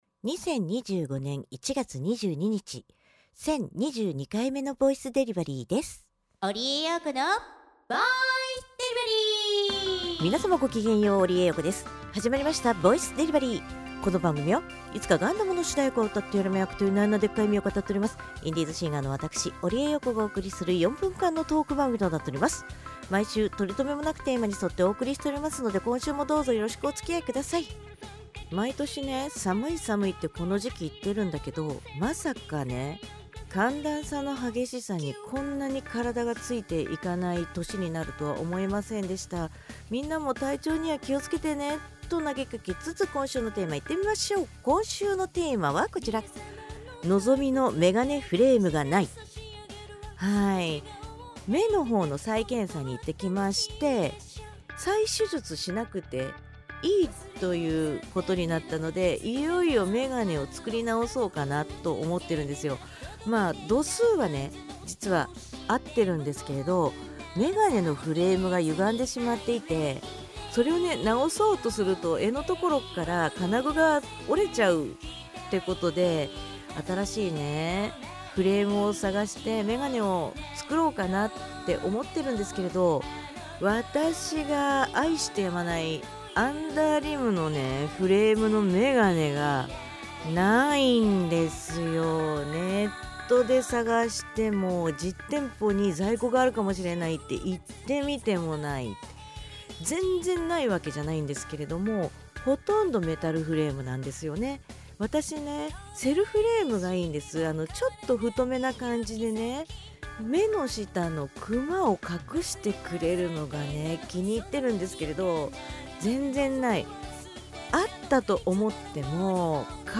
毎週水曜日か木曜日更新の４分間のトーク番組（通称：ぼいでり）時々日記とTwitterアーカイブ